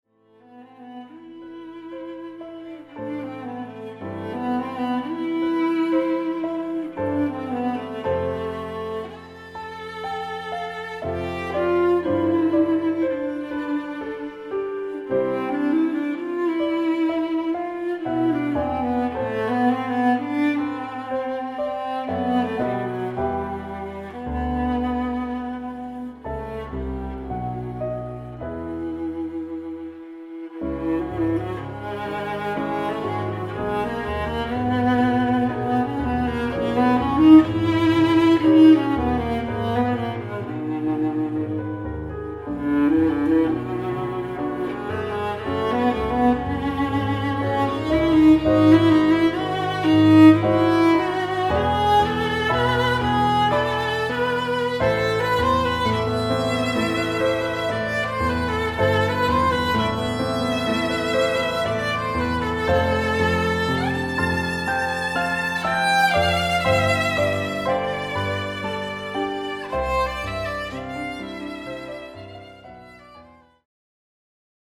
(in der Trauerhalle)
klassische Musik